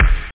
Amiga 8-bit Sampled Voice
1 channel
bassdrum1.mp3